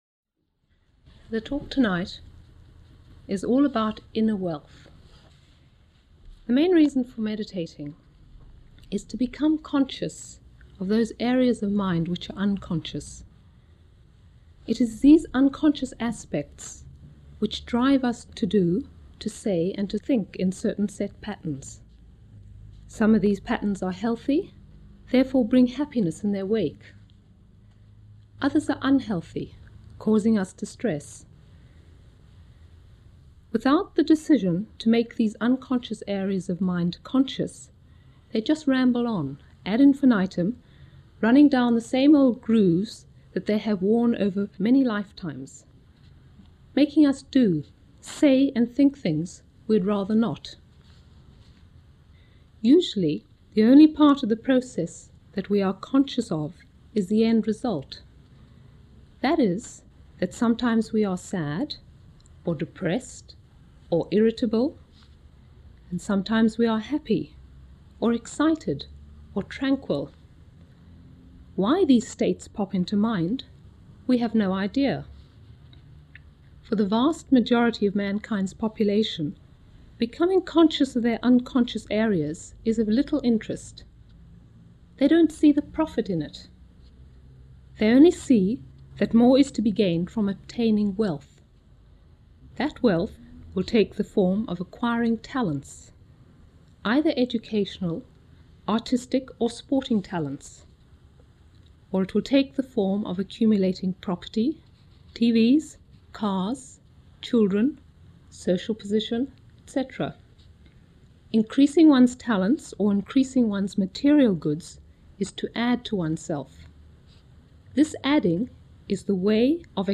This talk was given in March 1984